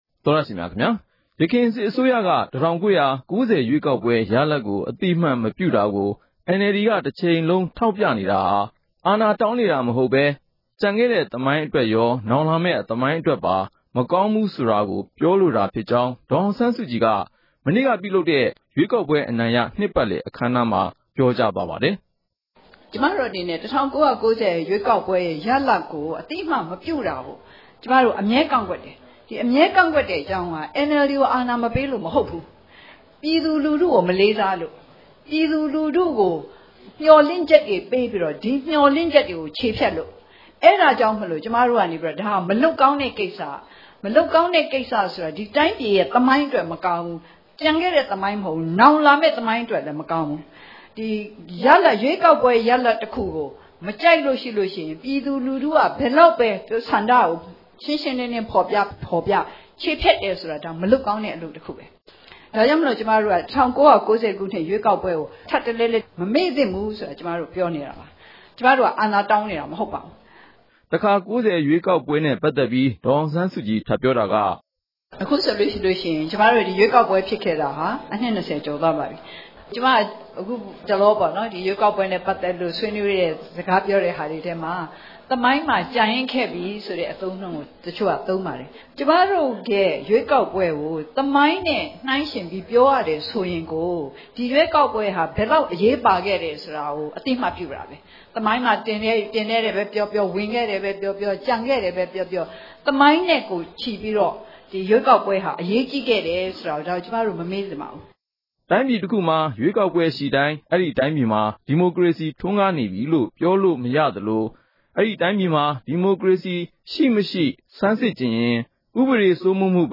ရန်ကုန်မြို့ ရွှေဂုံတိုင် NLD ပါတီဌာနချုပ်မှာ မနေ့က ကျင်းပတဲ့ အခမ်းအနားမှာ ဒေါ်အောင်ဆန်းစုကြည်က အဲဒီလို ပြောဆိုခဲ့တာပါ။